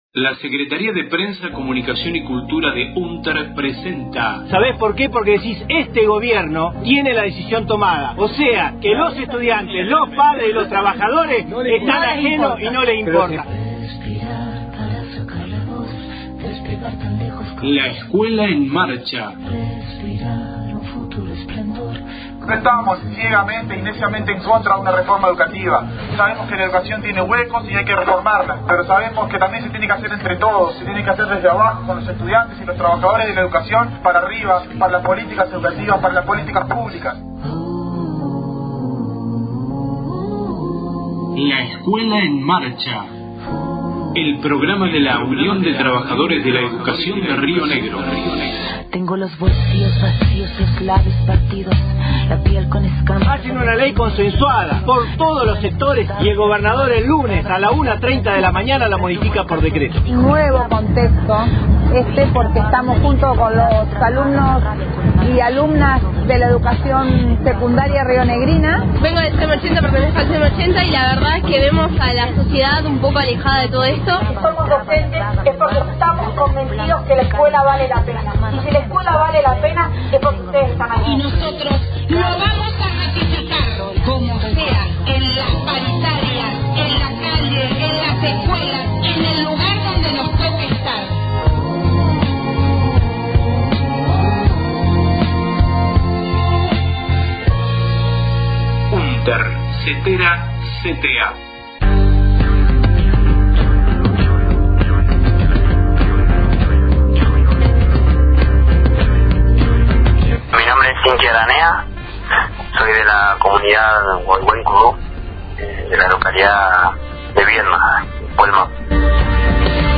Audio LEEM radio, 14/04/17. Voces de la charla debate "Encuentro con la Tierra", realizada en Roca – Fiske Menuco, el 12 de abril. El Pueblo Mapuche convoca a la marcha provincial por el derecho a la tierra sin contaminación, en rechazo del nuevo Código de Tierras Fiscales que el gobierno provincial quiere imponer sin consulta previa, libre e informada (establecida por el convenio 169 de la Organización Internacional del Trabajo, la Declaración de las Naciones Unidas sobre Derechos de los Pueblos Indígenas y la Declaración Americana sobre Derechos de los Pueblos Indígenas).